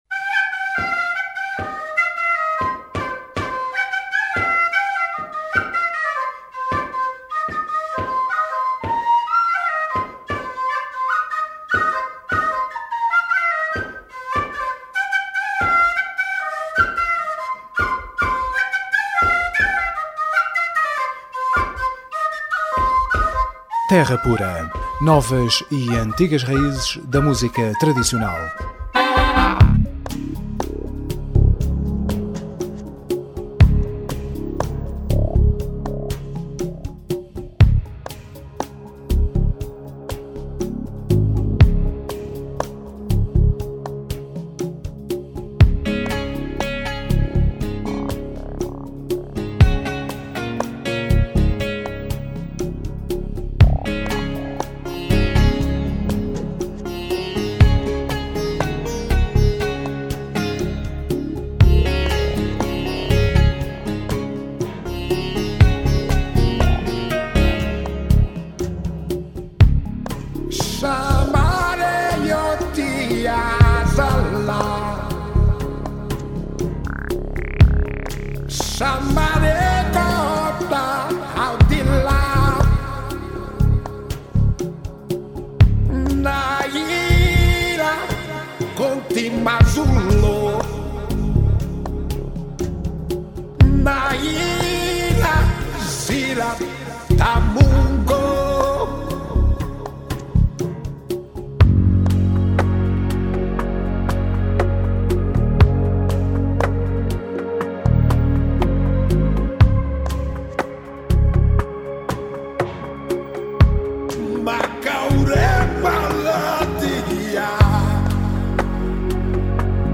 Terra Pura 11MAI12: Entrevista Fernando Girão